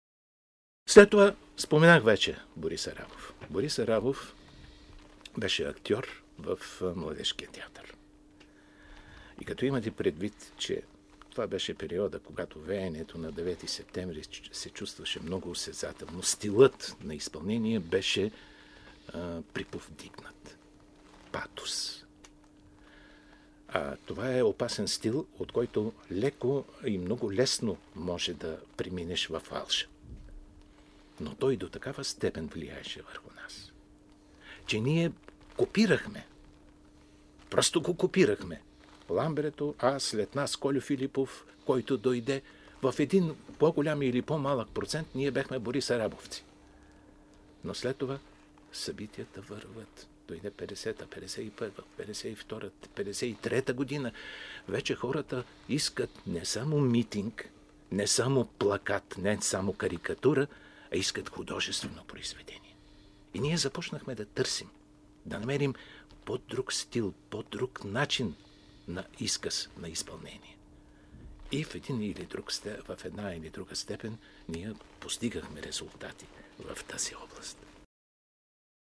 Българското радио търси сътрудничеството му, защото гласът му е радиофоничен, музикално пластичен, поддаващ се на различни жанрови изисквания.